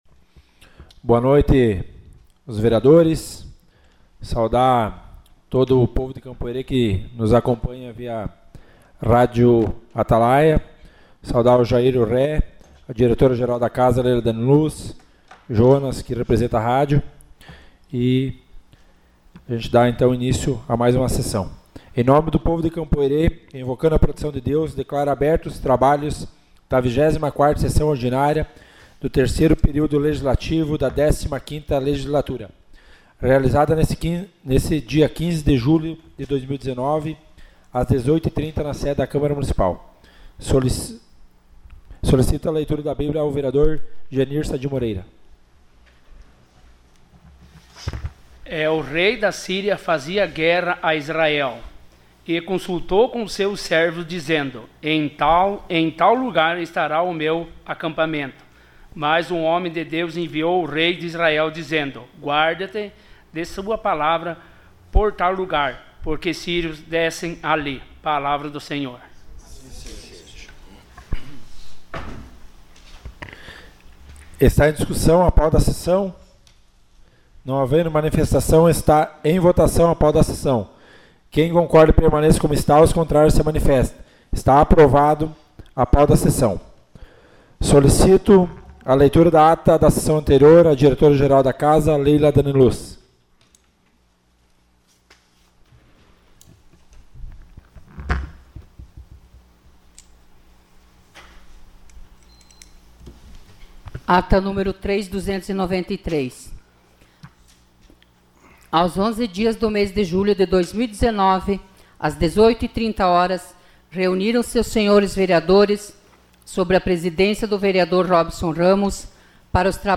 Sessão Ordinária 15 de julho de 2019.